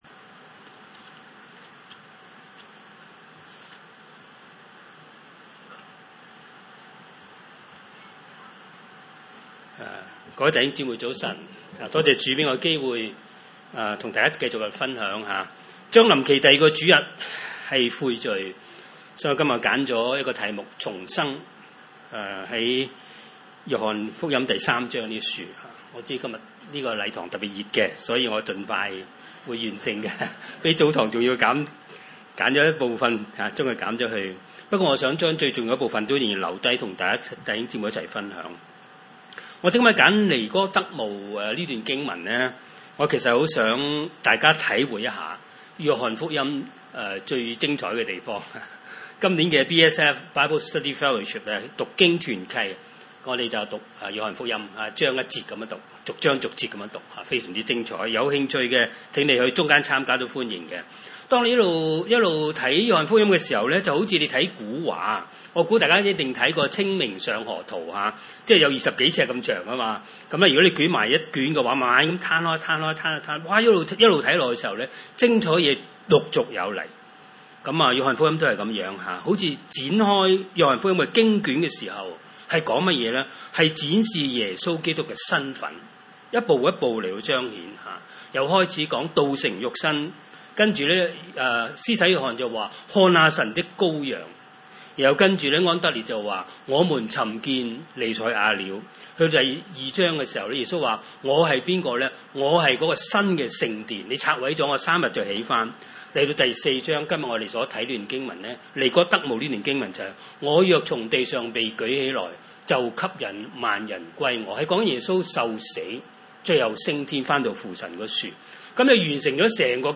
經文: 約翰福音 三：1-15 崇拜類別: 主日午堂崇拜 1有一個法利賽人，名叫尼哥底母，是猶太人的官。